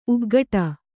♪ uggaṭa